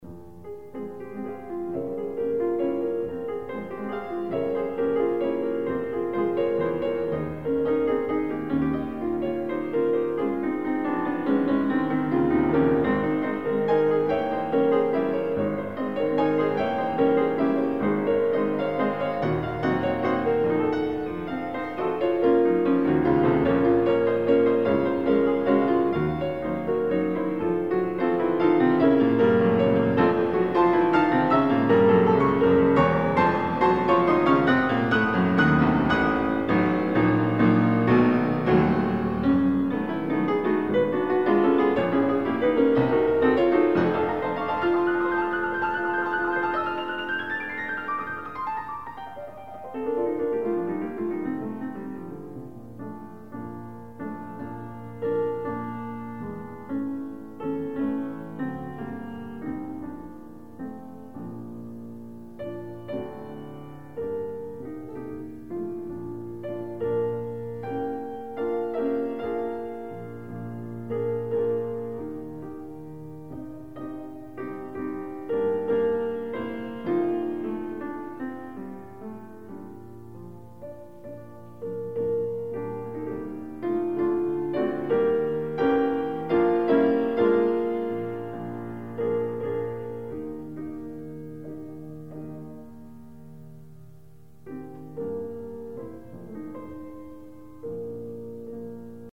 Additional Date(s)Recorded September 12, 1977 in the Ed Landreth Hall, Texas Christian University, Fort Worth, Texas
Sonatas (Piano)
Etudes
Short audio samples from performance